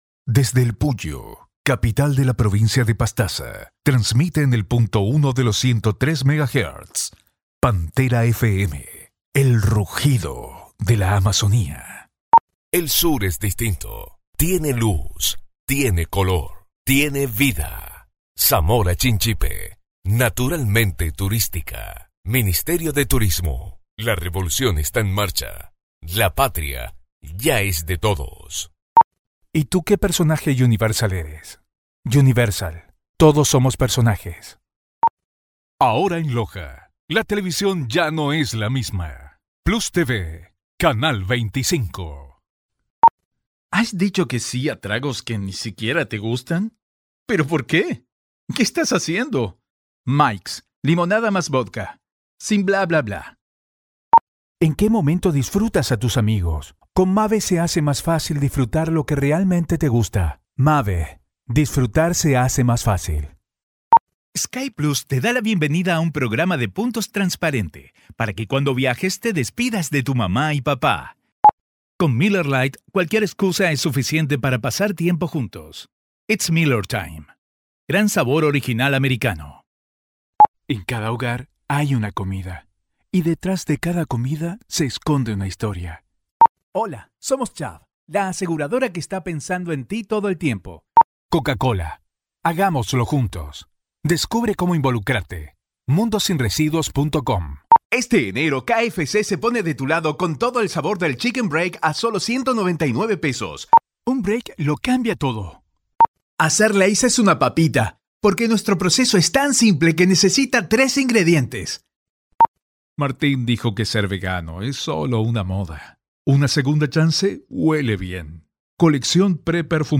Versatile, Conversational, Real, Natural, Friendly, Warm, Caring, Playful, Upbeat, Fun, Sensual, Flirty, Energetic, Happy, Genuine, Girl-Next-Door, Youthful, Authentic, Sassy, Sarcastic, Approachable, Modern, S...
With a rich, authentic Scottish lilt and years of professional experience, I have voiced for some of the world's leading brands including Starbucks, The Macallan and AXA Insurance.